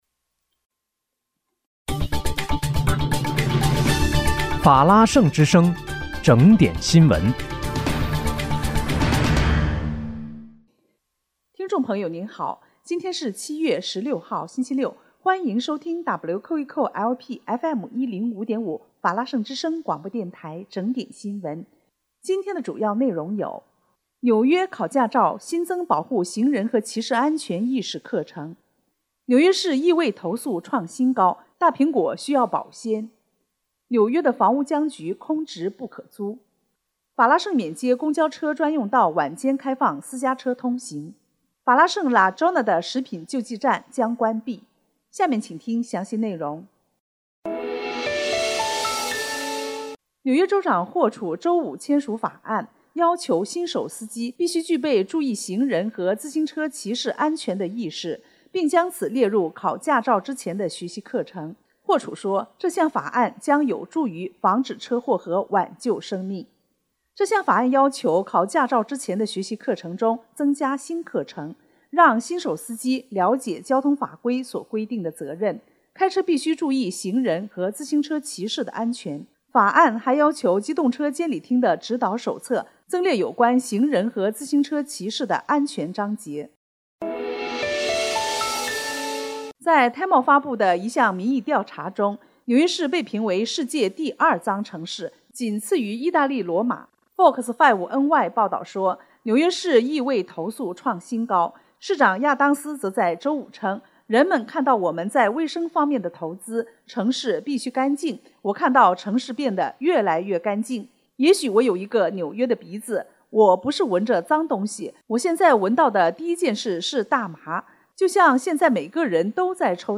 7月16日（星期六）纽约整点新闻